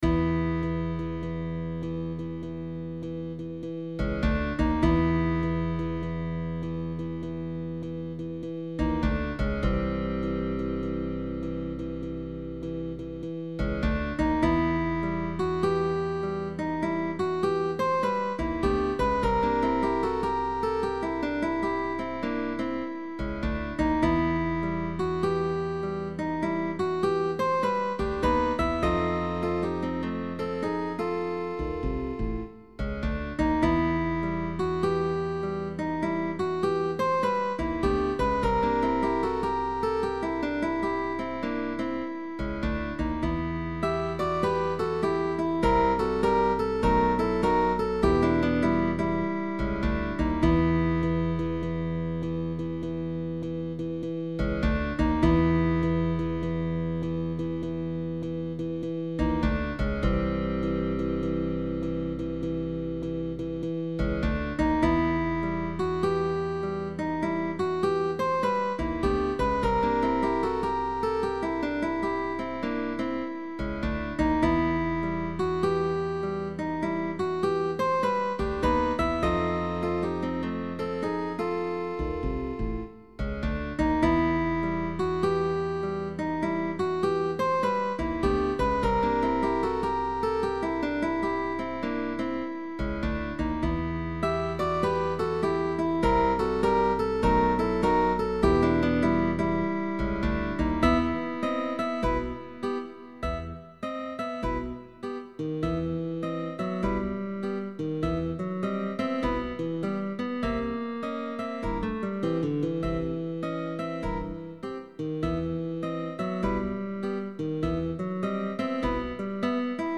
Guitar quartet sheetmusic.
instrumental composition
With bass optional.